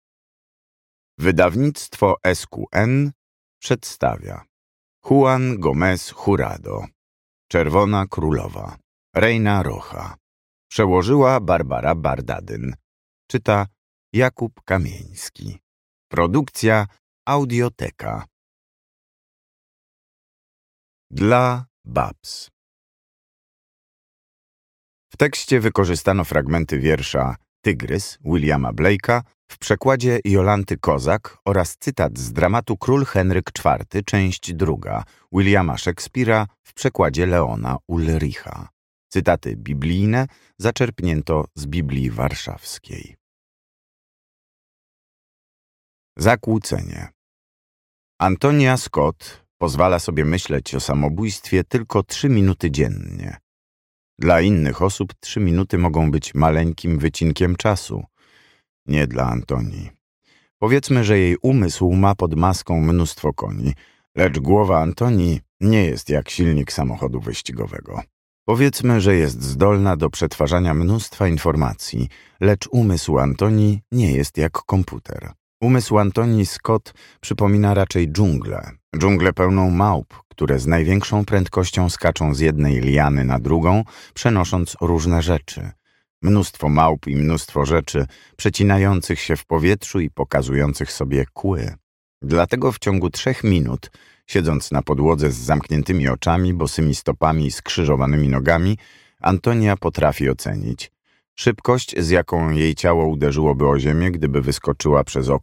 Reina Roja. Czerwona Królowa | Audiobook w SQN Store